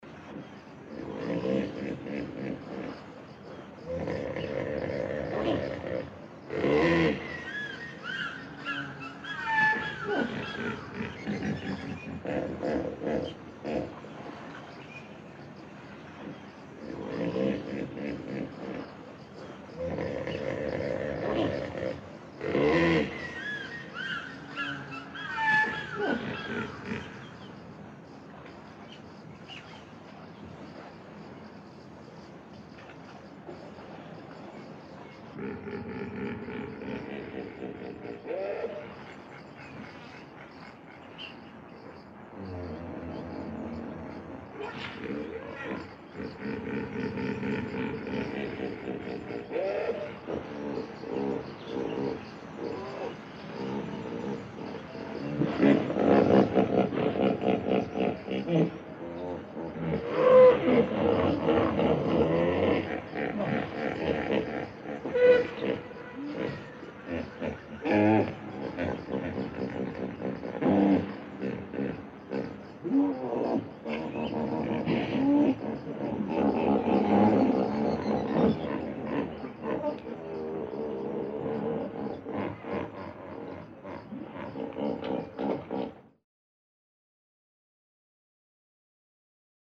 На этой странице собраны натуральные записи, которые подойдут для творческих проектов, монтажа или просто любопытства.
Бегемот наслаждается отдыхом среди природы